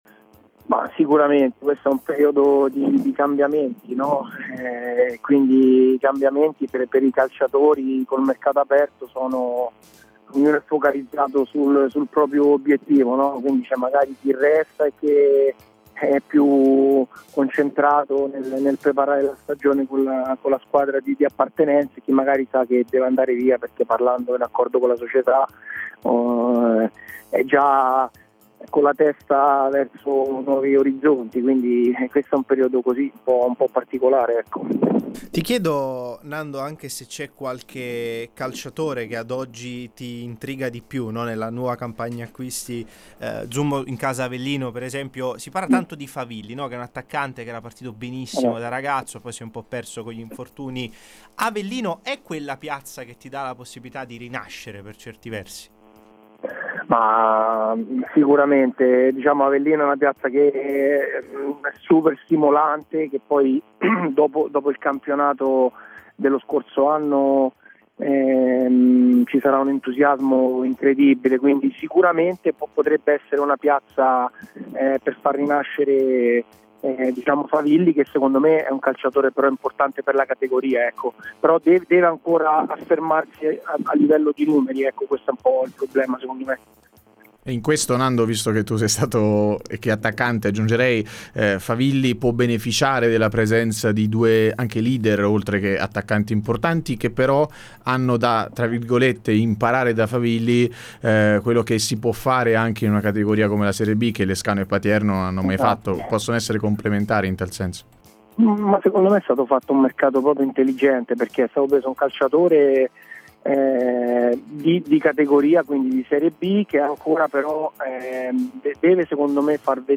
Ospite di Radio Punto Nuovo